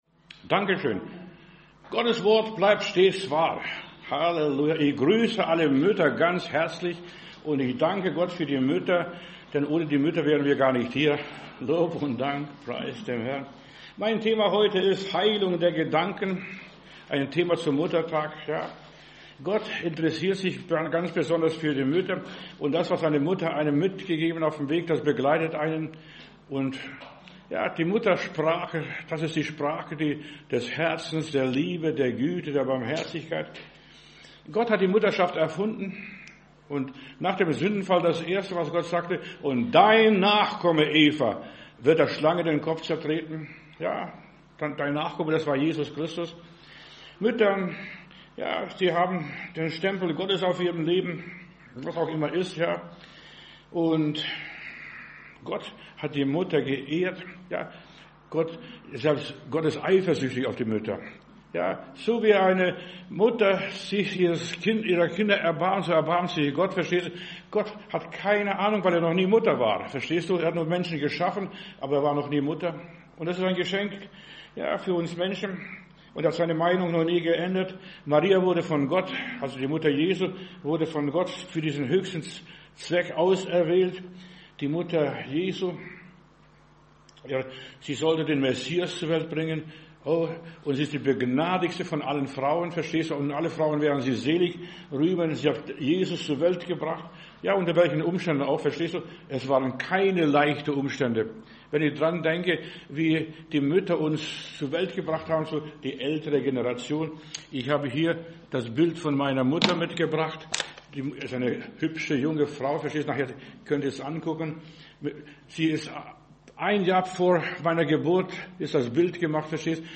Predigt herunterladen: Audio 2025-05-11 Heilung der Gedanken Video Heilung der Gedanken